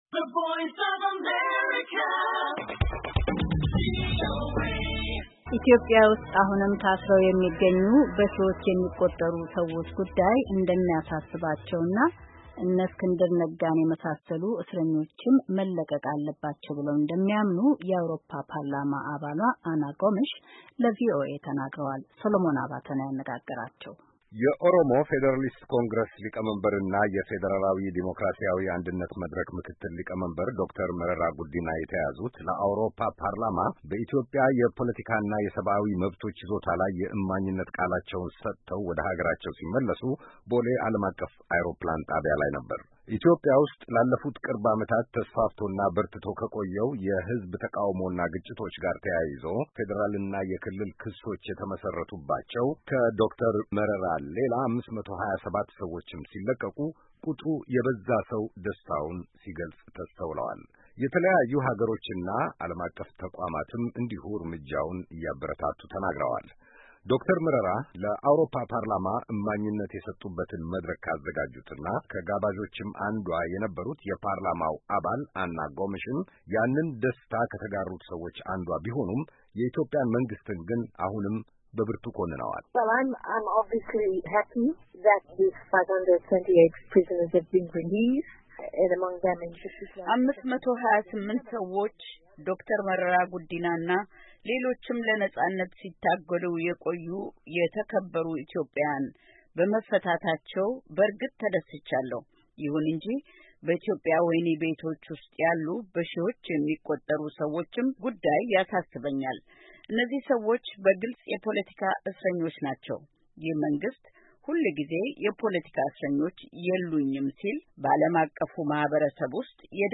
Ana Gomex, EU Partliament Member on Ethiopian current political situation. interview with VOA.